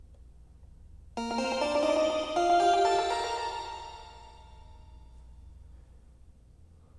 System-1 Memory Set 4: Pads and Bells